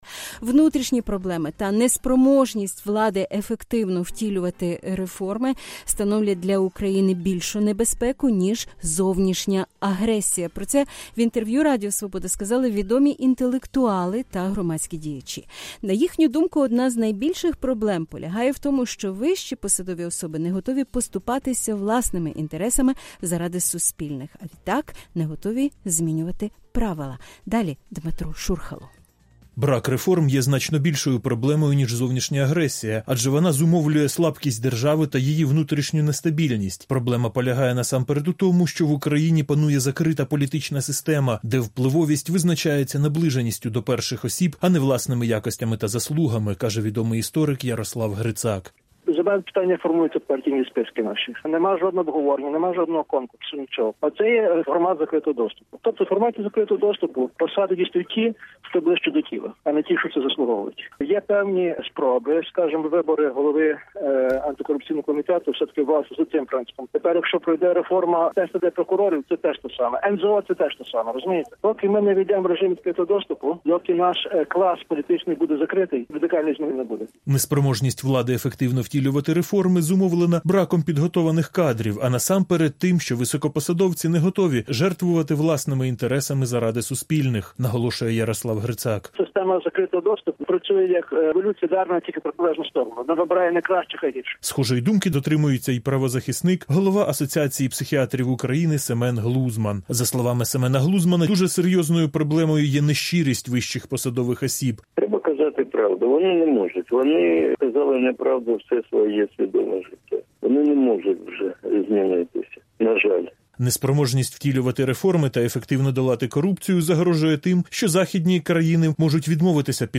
Про це в інтерв’ю Радіо Свобода сказали відомі інтелектуали та громадські діячі.